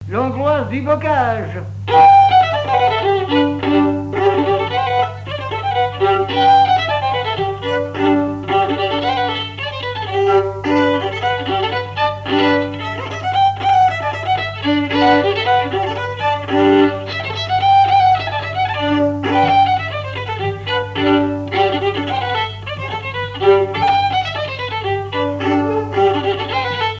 Mémoires et Patrimoines vivants - RaddO est une base de données d'archives iconographiques et sonores.
danse : angoise, maristingo
Répertoire du violoneux
Pièce musicale inédite